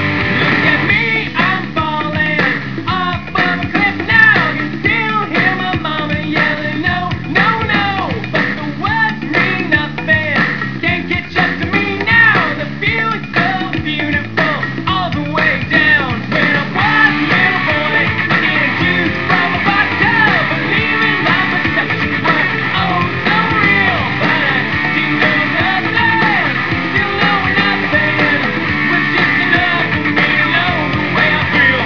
Unfortunately, the clips are not of best quality.